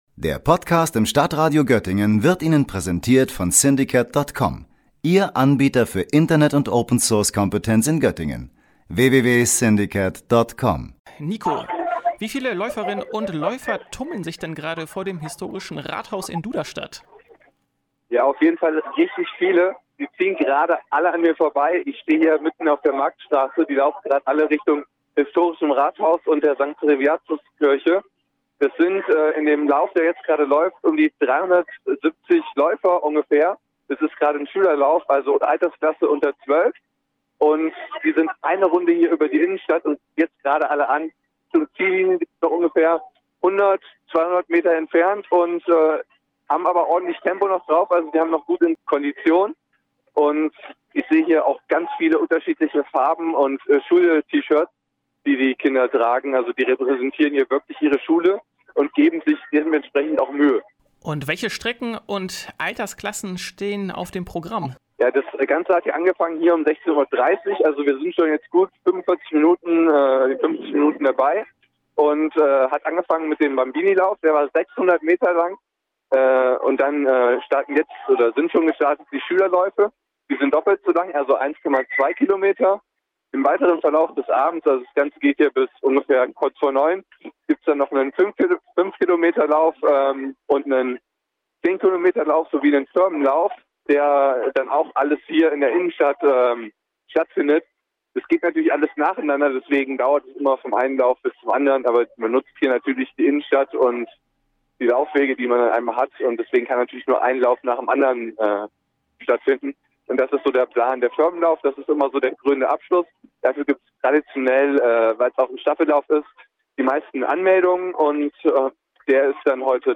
Mehrere Strecken sind gefragt: Live vom Sparkassenlauf in Duderstadt